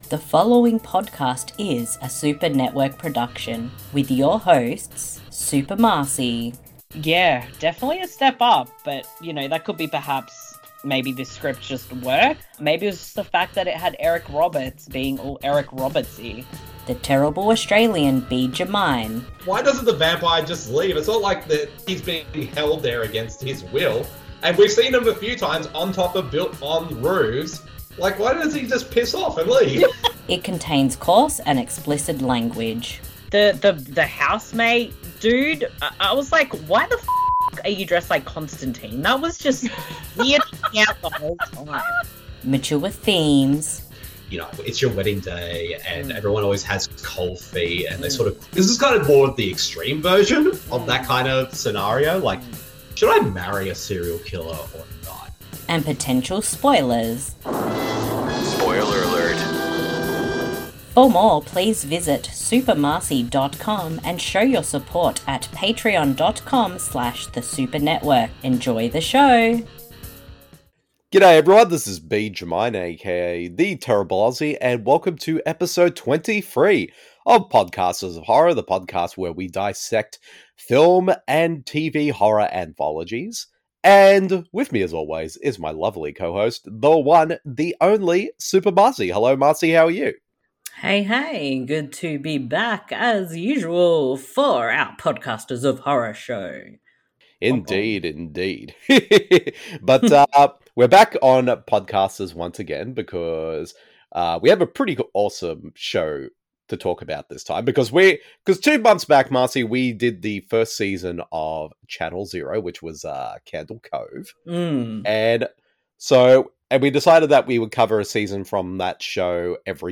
Podcasters Of Horror Episode 23 Channel Zero Season 2 ‘No-End House’ Discussion